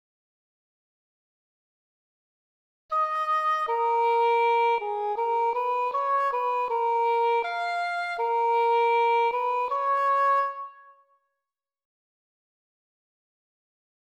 …in inversion (upside down):
Fugue d# inversion
fugue-d-inversion.mp3